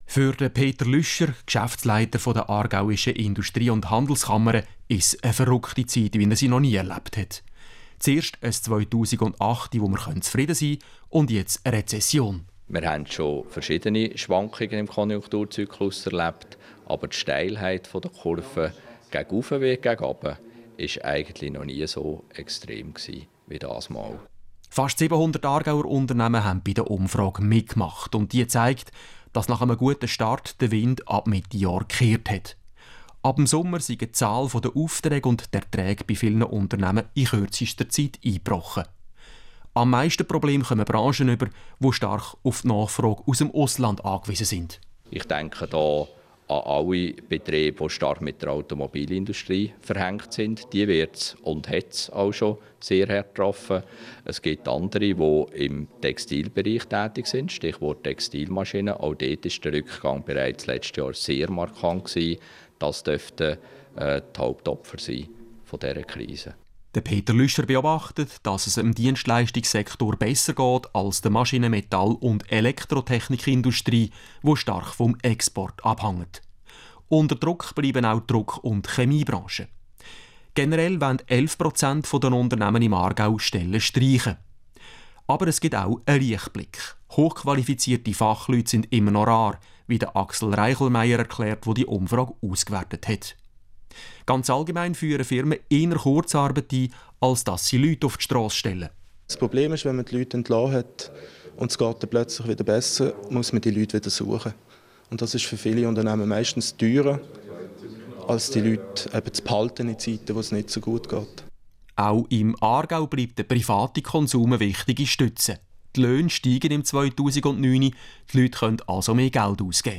Interview zur AIHK-Wirtschaftsumfrage 2009